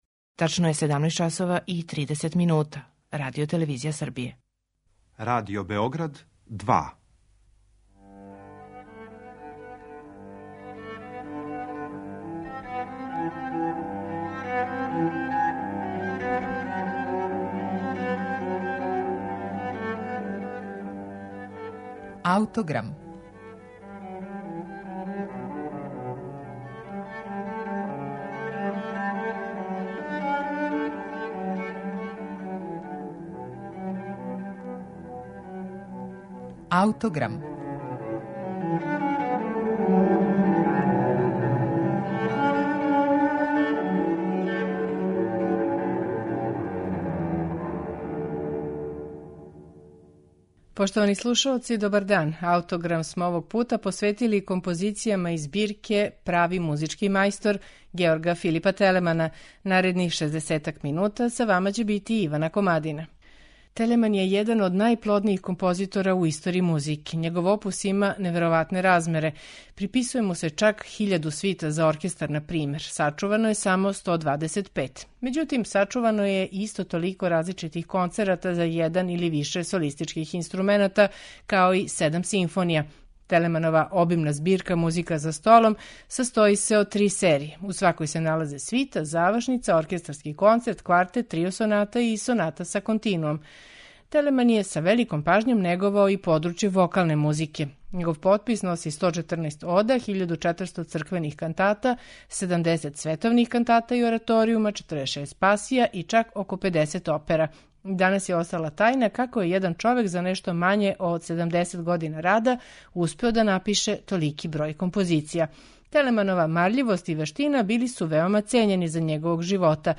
обоа
фагот
чембало.